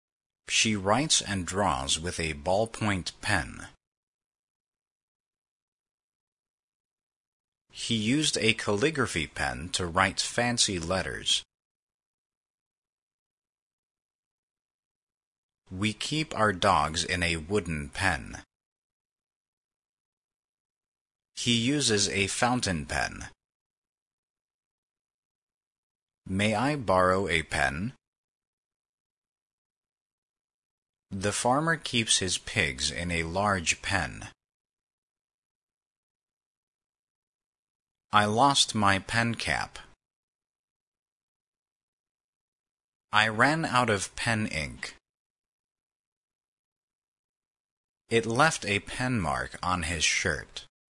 pen-pause.mp3